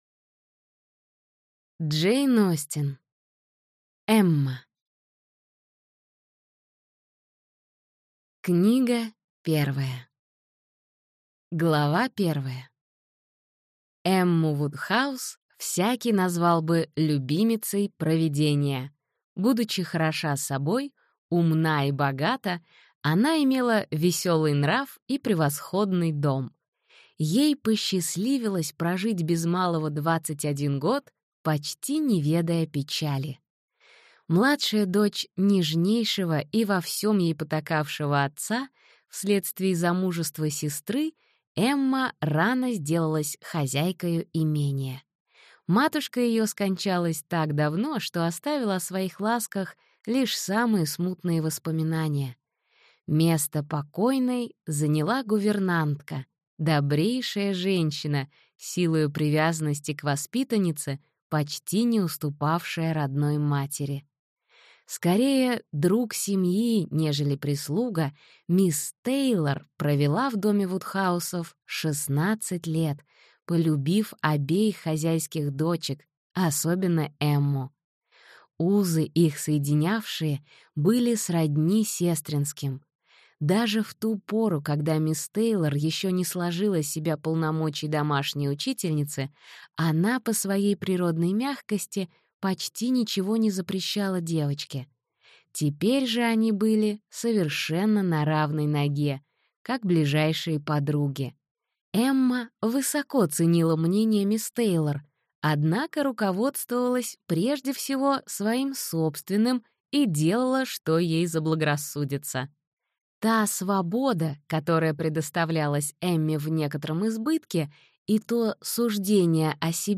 Аудиокнига Эмма. Книга 1 | Библиотека аудиокниг